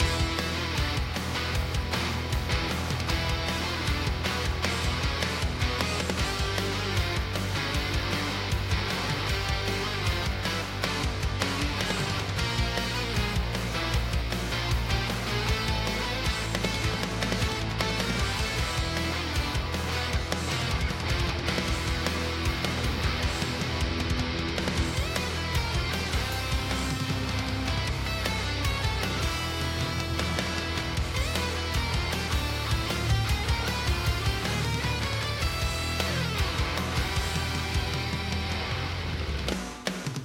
dans une version bien pêchue et bien sûr HD.